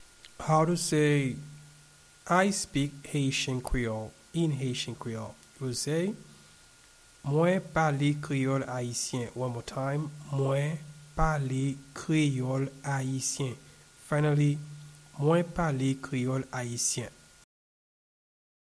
I-speak-Haitian-Creole-in-Haitian-Creole-Mwen-pale-Kreyol-Ayisyen.mp3